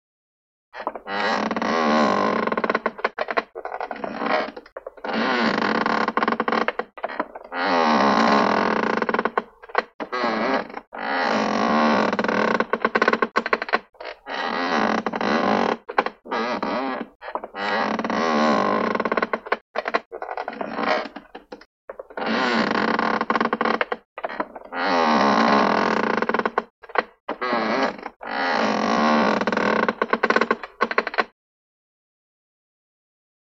Sailing Ship; Creaking; Ship Creaking.